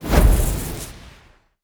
sfx_skill 03_1.wav